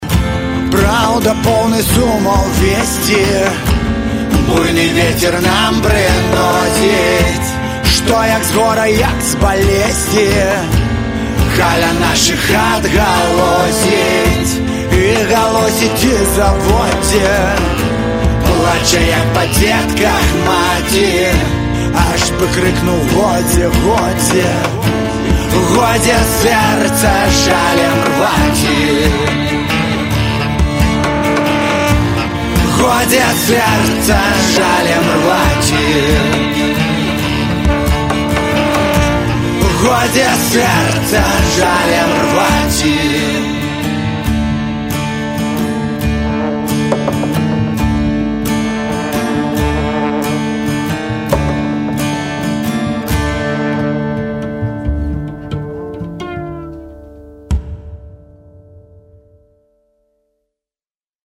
• Качество: 128, Stereo
гитара
мужской вокал
виолончель
фолк-рок
белорусский рок
Контрабас